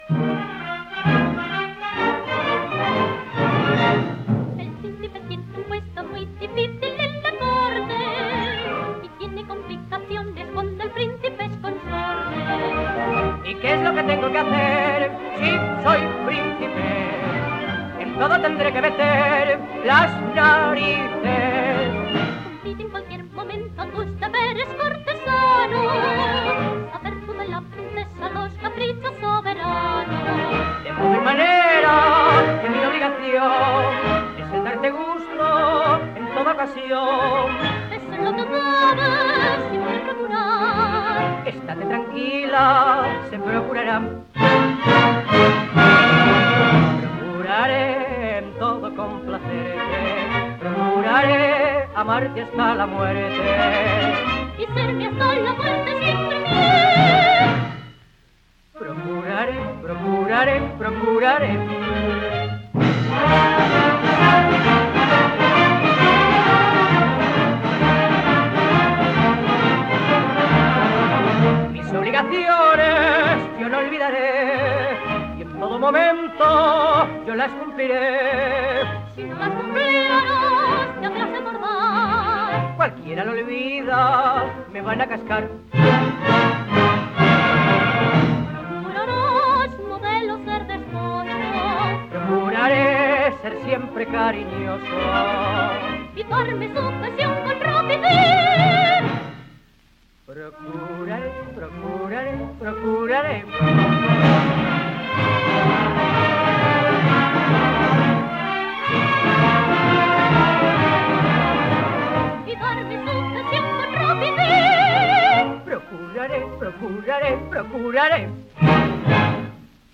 (dueto-marcha).
78 rpm.